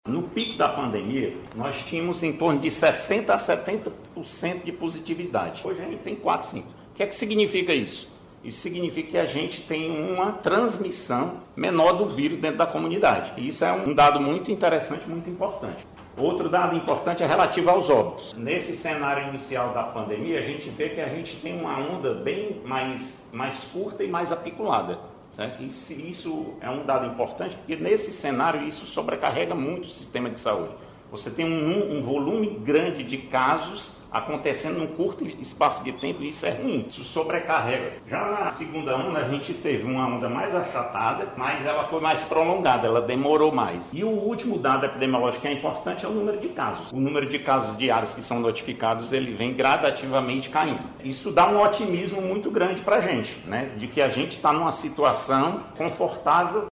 Ao mostrar o cenário epidemiológico do Ceará, o secretário da Saúde, Marcos Gadelha, afirmou que o Estado atravessa uma situação confortável, com diminuição de óbitos, casos e da taxa de transmissão.